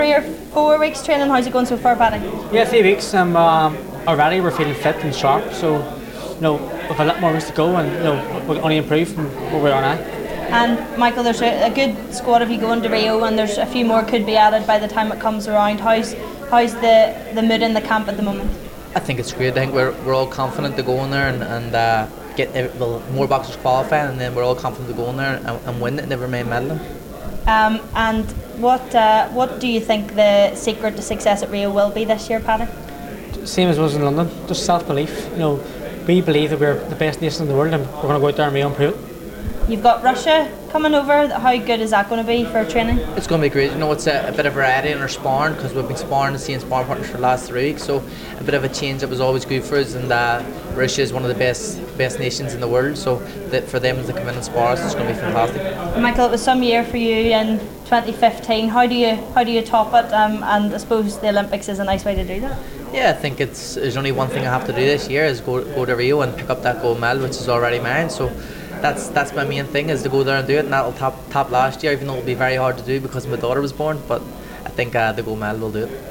U105 at the Belfast Telegraph Sports Awards
We catch up with local boxing stars Paddy Barnes & Michael Conlan.